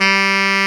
SAX TENORF0G.wav